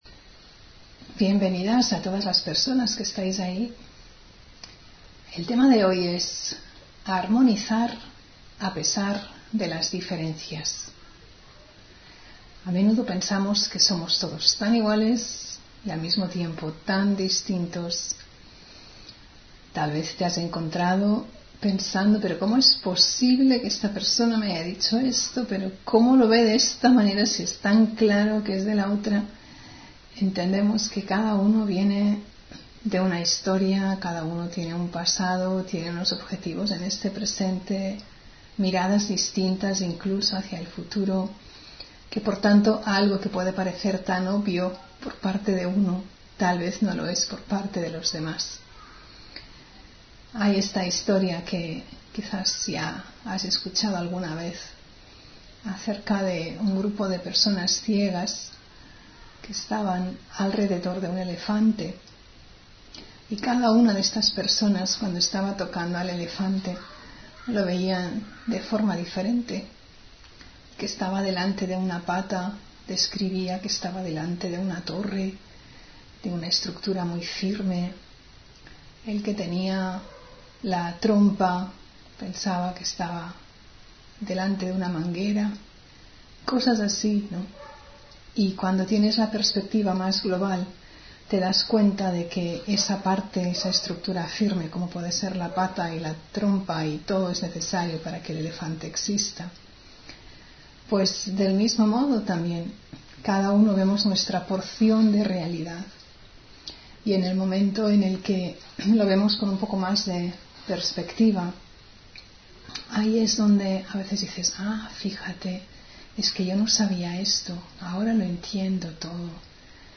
Meditación y conferencia: La música del alma (3 Mayo 2024)